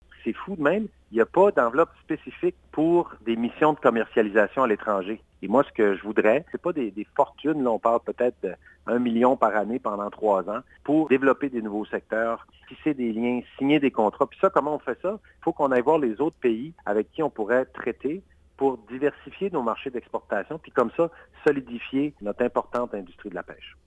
D’ici le scrutin, la salle des nouvelles de CFIM vous présente des entrevues thématiques réalisées chaque semaine avec les candidat(e)s. Les sujets abordés sont les suivants: Environnement (31 mars au 2 avril), Territoire et insularité (7 au 9 avril) et Perspectives économiques (14 au 16 avril).